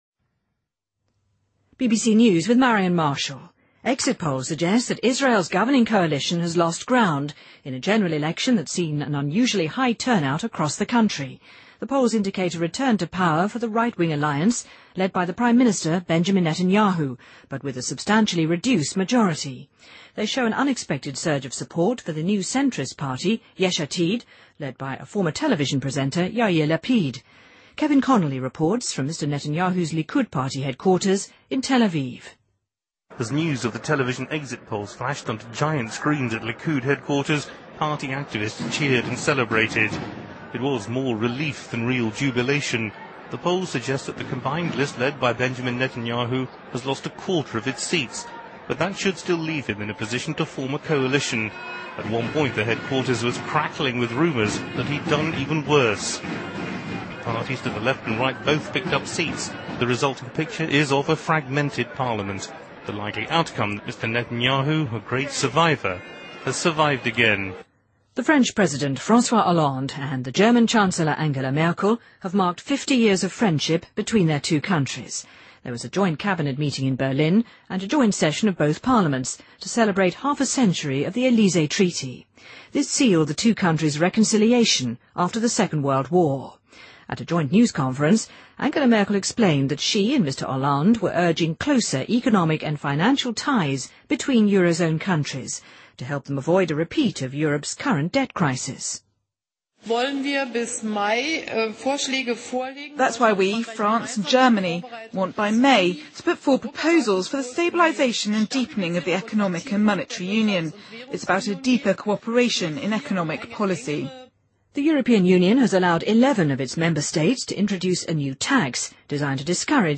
BBC news:欧盟已批准11位成员国引入新的税收政策|BBC在线收听